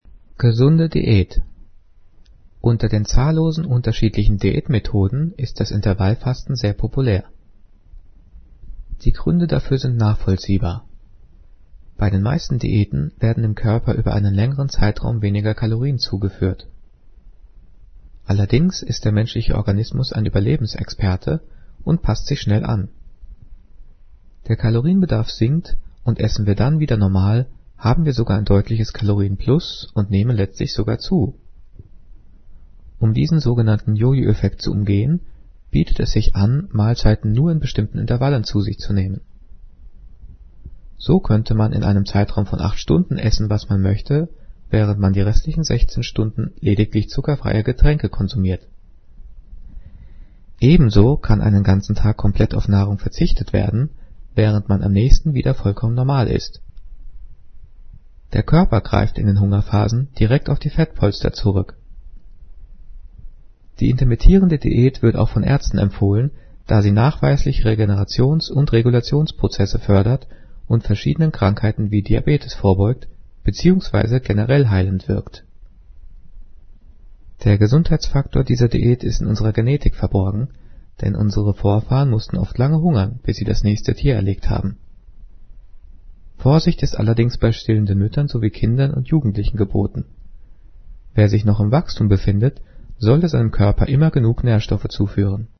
Diktat: "Gesunde Diät" - 9./10. Klasse - Dehnung und Schärfung
Gelesen:
gelesen-gesunde-diaet.mp3